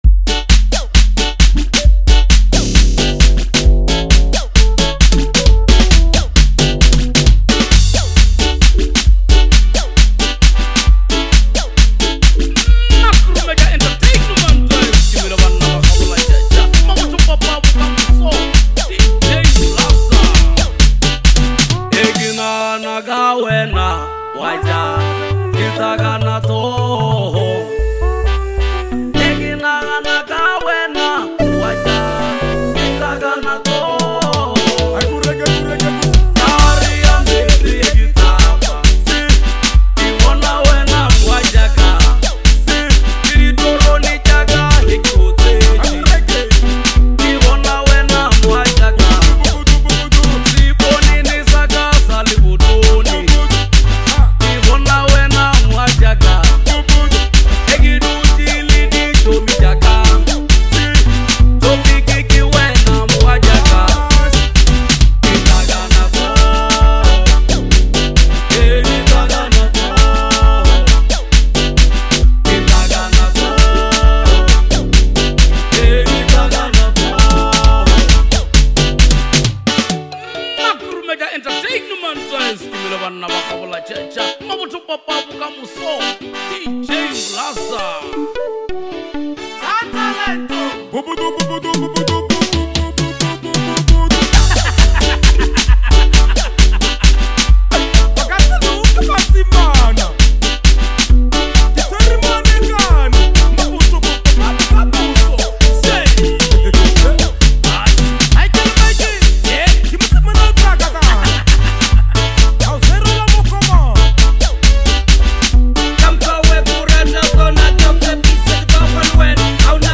Talented vocalist